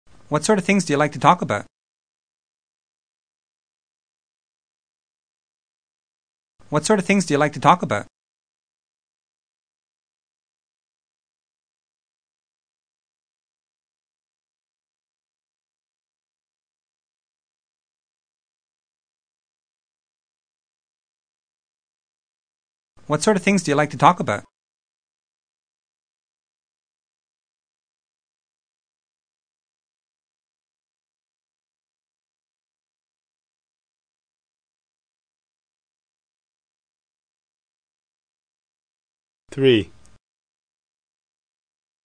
Speech Communication Dictation
Form-Focused Dictation 1: Wh. vs. Yes/No Questions (intonation patterns)